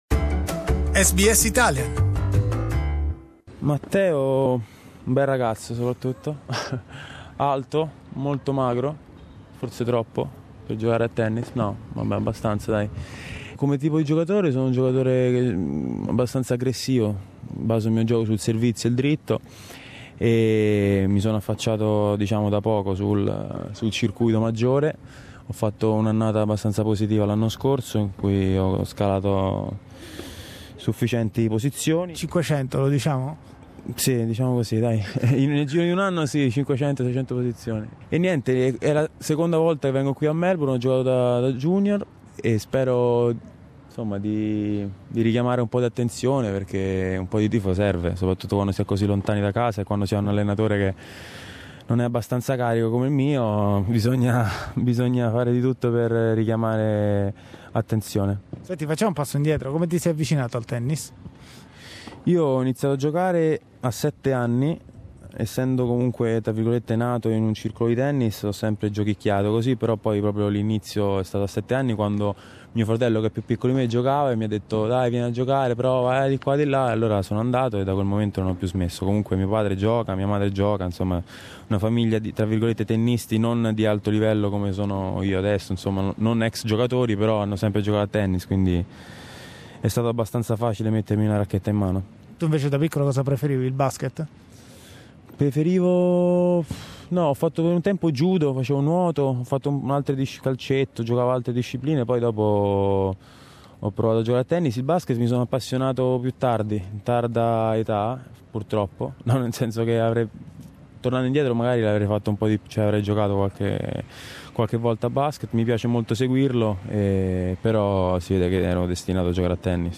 Matteo Berrettini negli studi di SBS
Riascolta qui l'intervista a Matteo Berrettini (gennaio 2018):